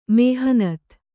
♪ mēhanat